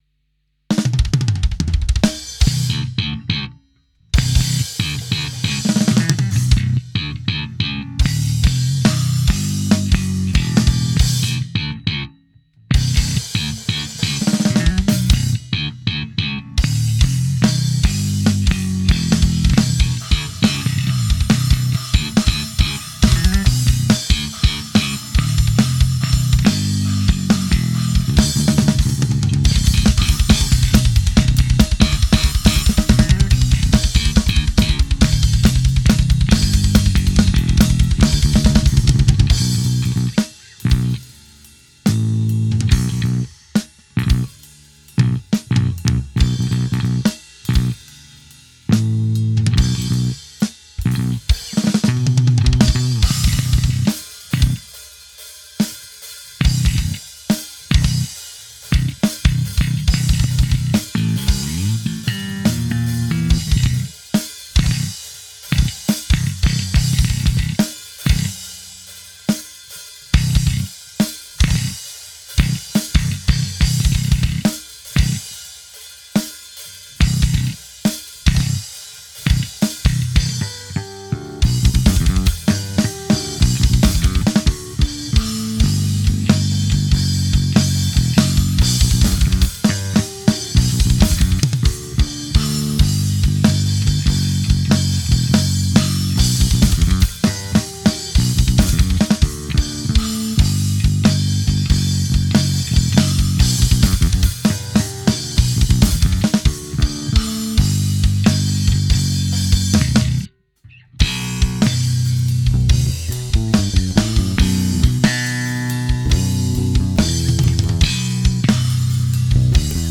drum 'n' bass version
vocals
keyboards
drums, bongos & cowbell
electric & acoustic guitars, acoustic bass
electric & acoustic bass, acoustic guitar